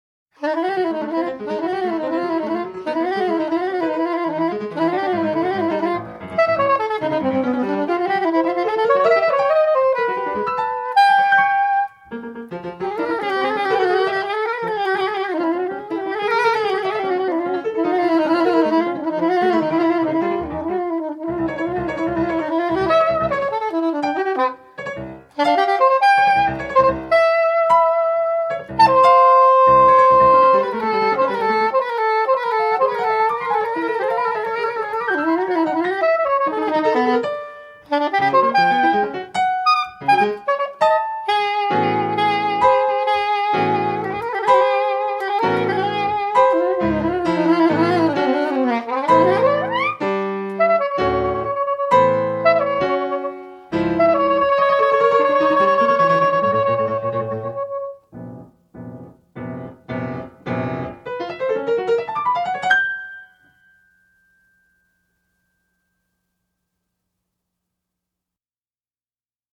piano, drums
flute, saxophone, shakuhachi
Spontaneous improvisation- no predetermined form.